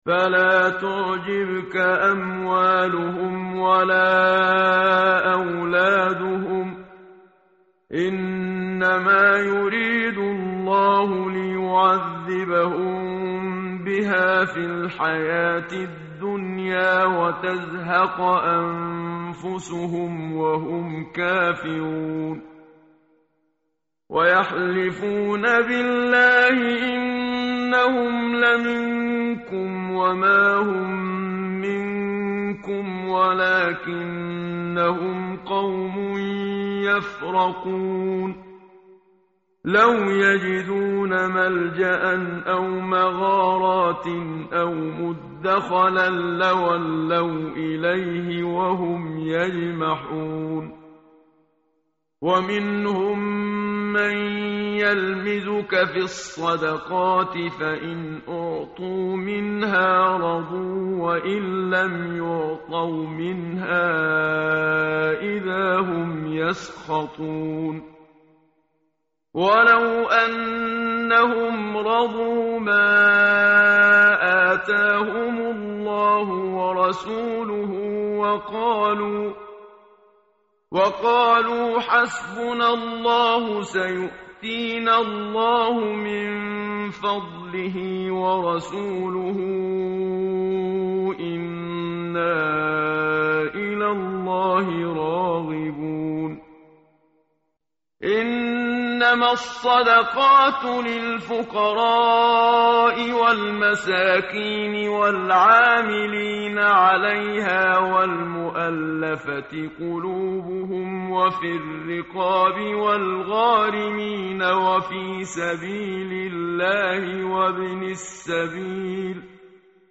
متن قرآن همراه باتلاوت قرآن و ترجمه
tartil_menshavi_page_196.mp3